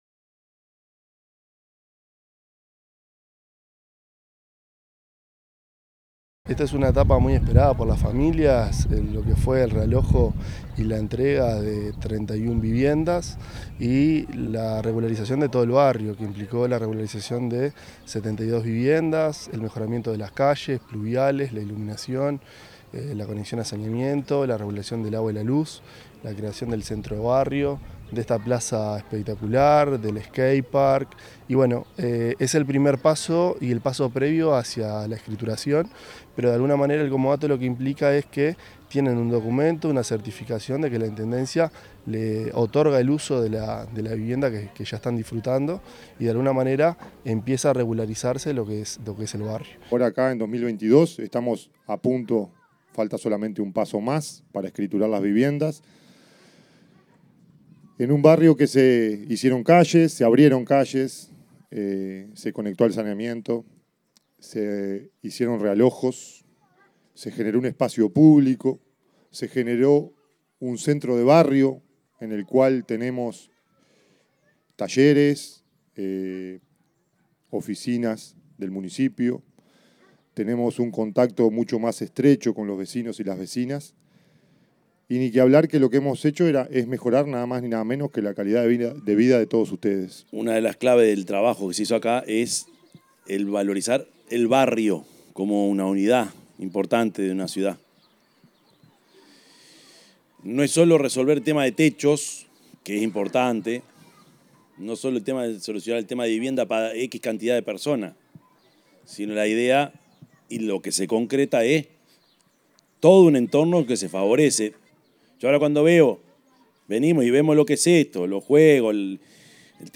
intendente_de_canelones_prof._yamandu_orsi_2.mp3